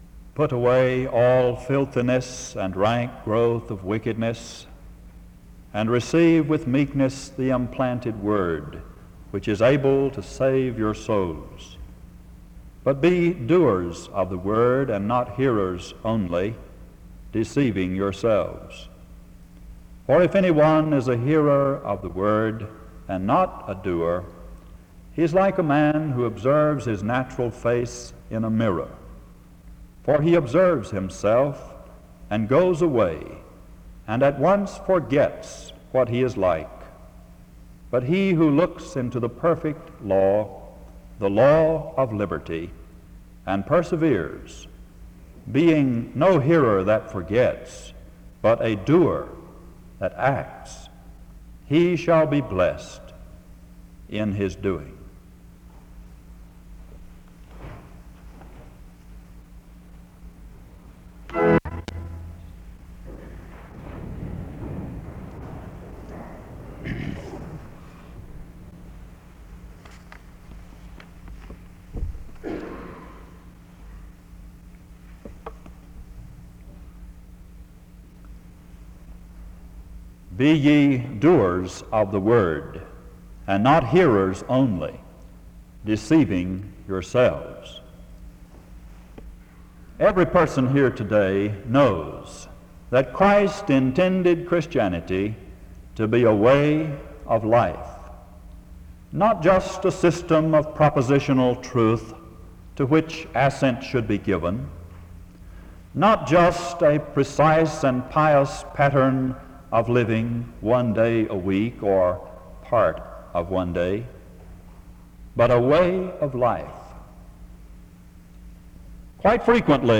The service begins with an opening scripture reading from 0:00-0:55.
SEBTS Chapel and Special Event Recordings SEBTS Chapel and Special Event Recordings